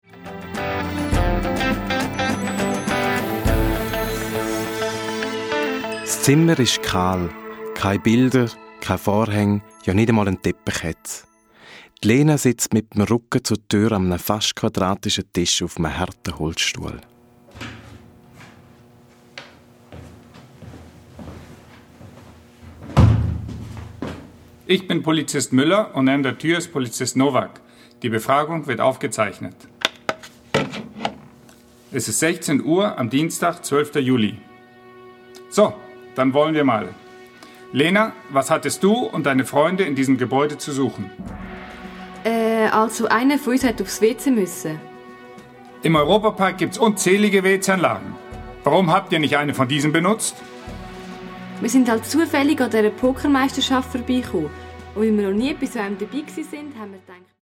Hörspiel-CD mit Download-Code